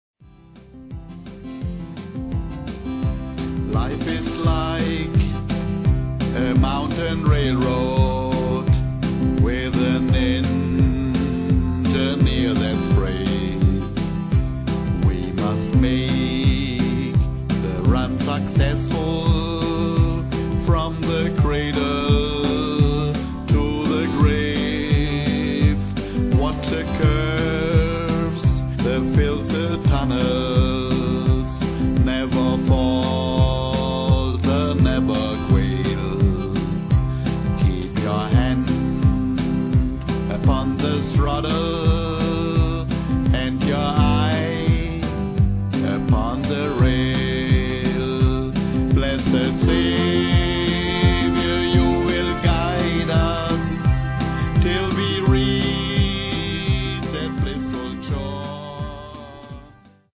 Gospels im Country-Style
Seit 2009 mache ich Studioaufnahmen.